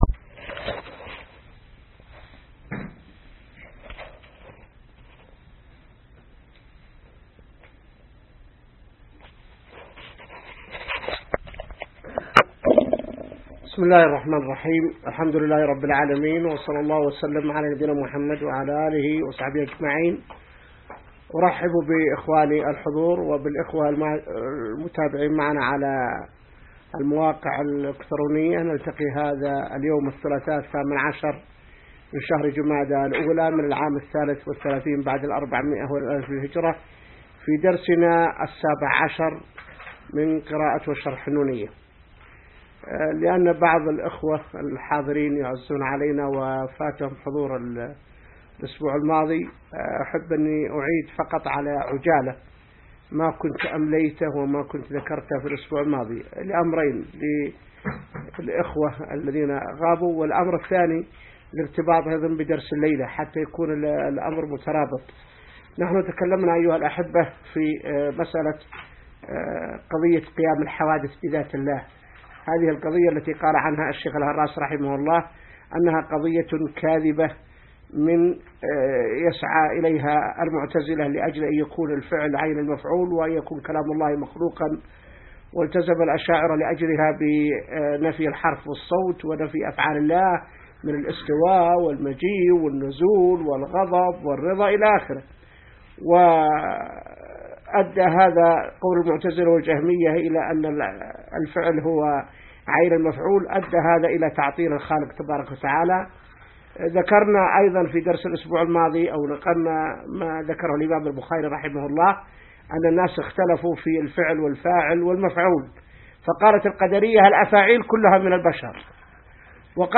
الدرس 17 من شرح نونية ابن القيم | موقع المسلم